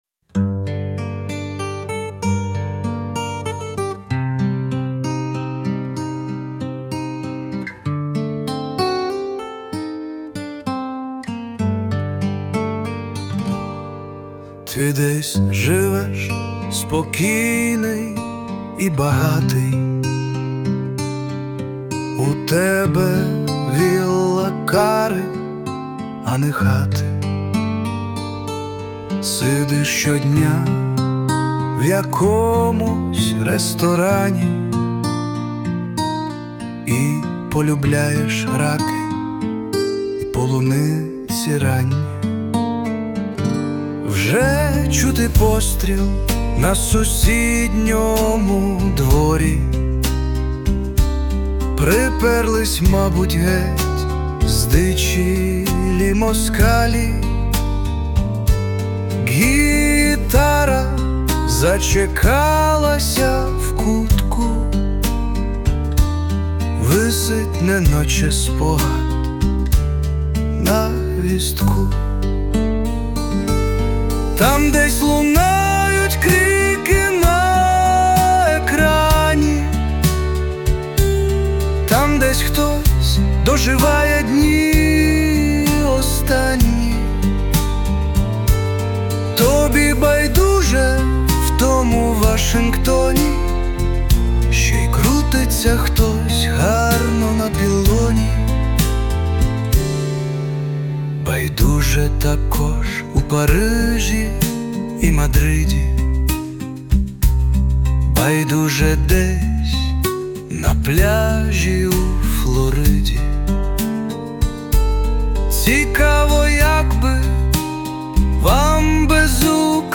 *у виконанні присутня допомога Suno
СТИЛЬОВІ ЖАНРИ: Драматичний